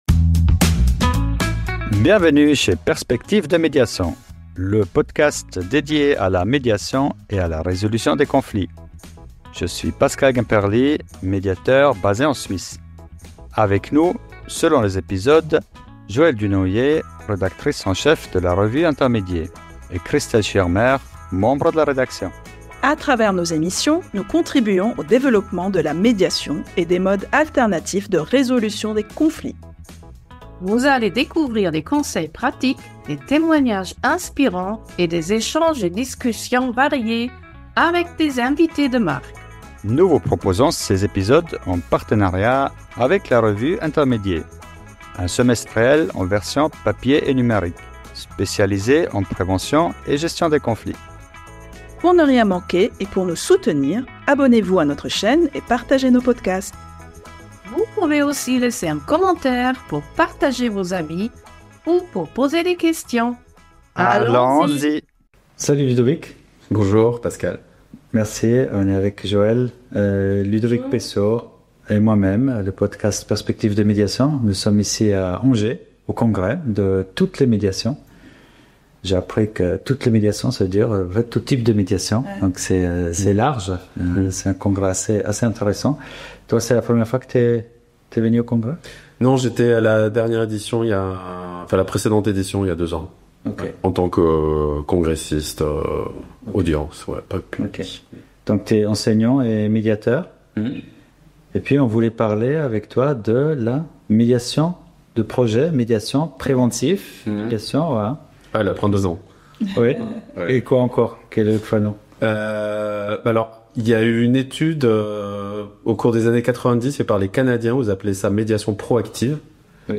Dans cet épisode enregistré au Congrès de toutes les médiations à Angers